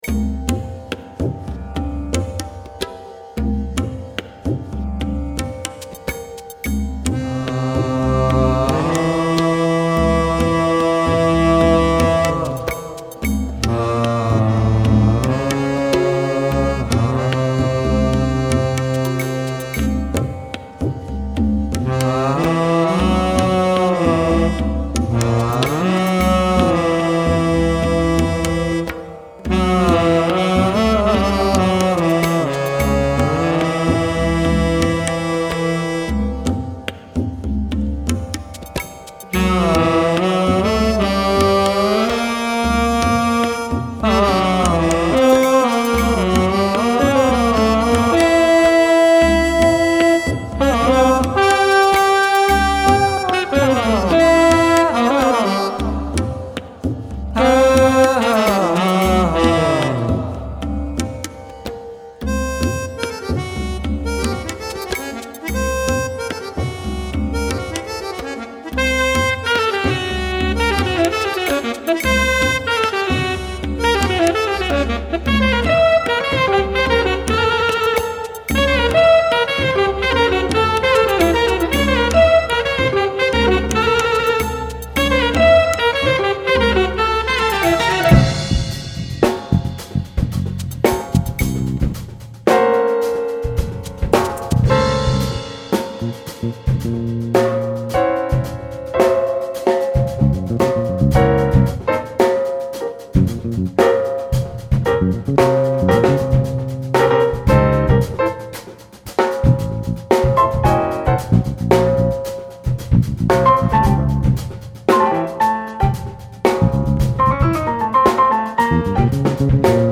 North Indian/Funk